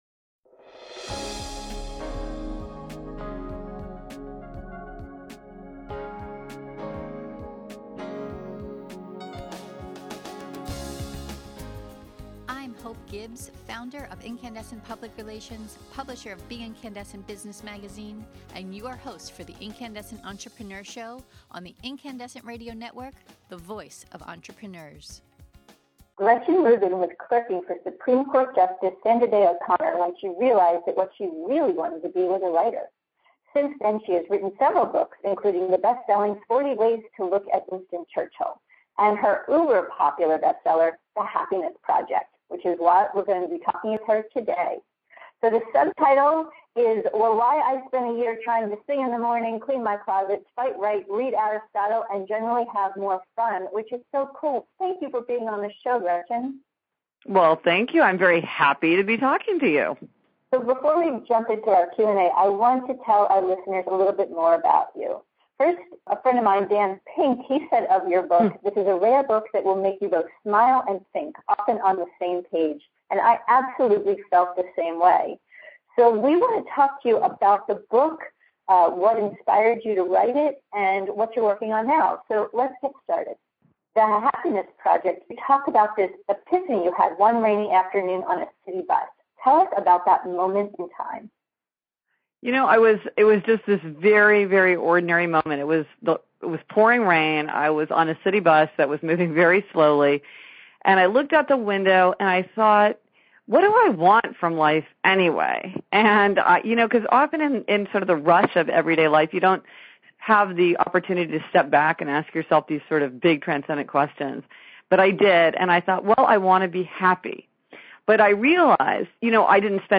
In this podcast interview you’ll learn: About Rubin’s epiphany, which she had one rainy afternoon on a city bus.